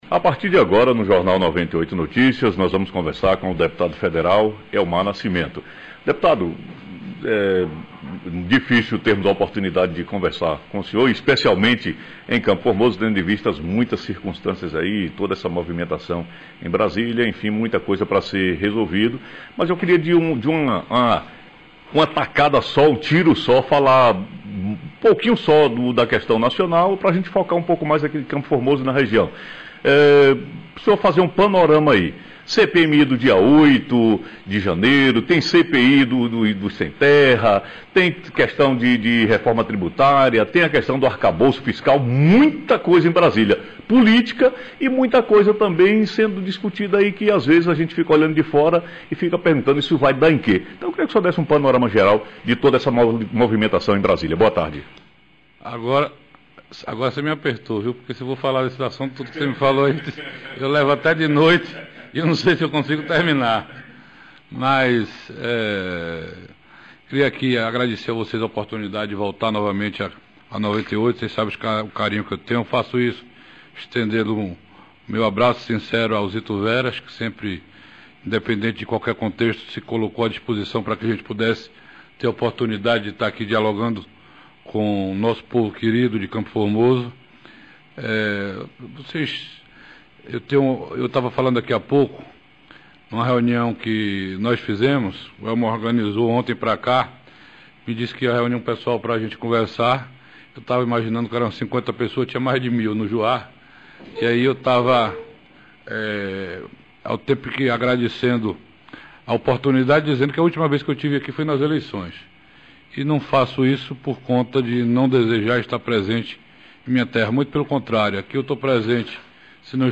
Entrevista- Deputado Federal Elmar Nascimento